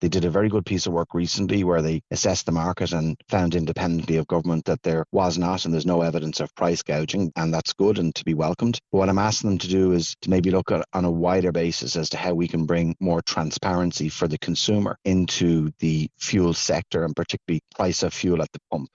Minister O’Brien says it’s important consumers don’t face “unfair competition or unjustified price increases”: